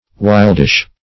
wildish - definition of wildish - synonyms, pronunciation, spelling from Free Dictionary
wildish.mp3